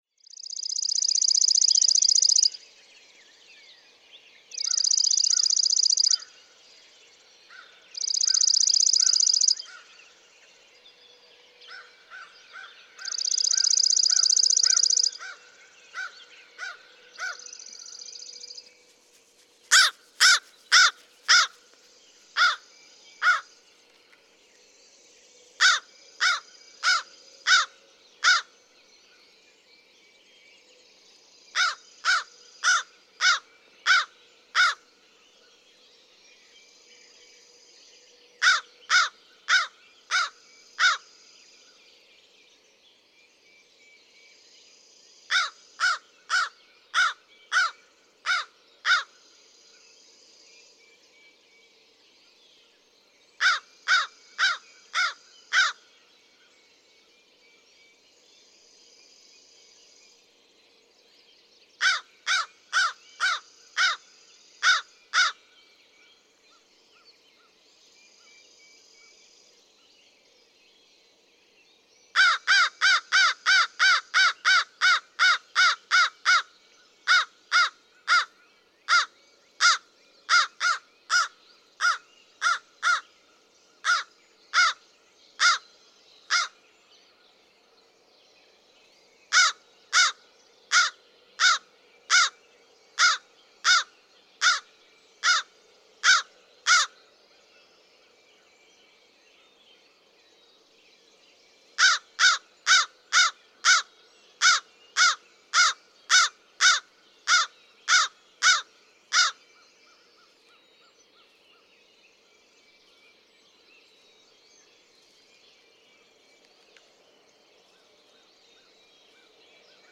American crow
A caw sampler from two crows calling from a treetop.
Hatfield, Massachusetts.
0:00-0:19. Three chipping sparrow songs in foreground as a crow approaches from a distance.
0:10-1:07. Slow, sharp, ca-ca-ca-ca from first crow to land in the nearby tree.
1:11. Frantic calling begins, at double the ca-ca-ca pace, then slows again.
3:17. Simultaneous calling again, both using the hoarse caw.
4:45. More simultaneous calling, and matching, with a slightly different caw version.
5:15. One bird begins with longer caw notes than the other, but midway the two birds match each other with the shorter caw again.
Throughout, and in headphones, listen for slightly different versions of the hoarse caw notes, and how the two birds often match each other's versions.
448_American_Crow.mp3